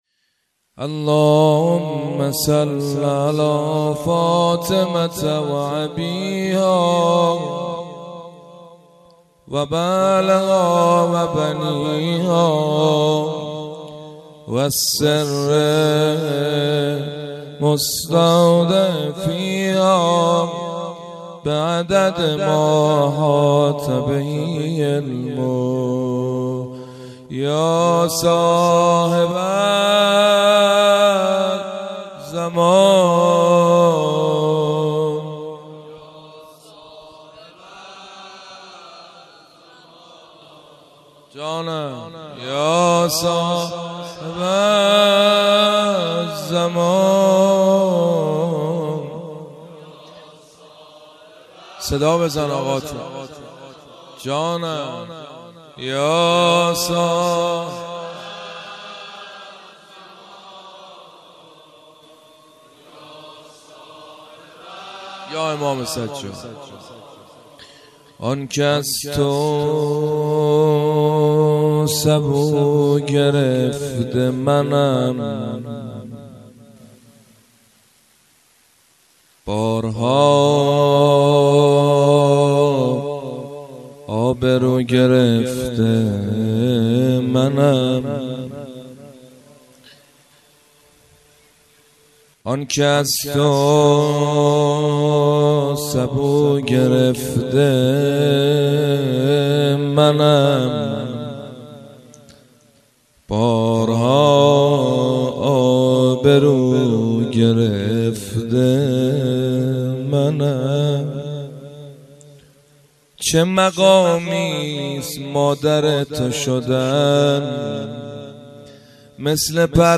مناسبت : ولادت امام سجاد علیه‌السلام
قالب : مناجات شعر خوانی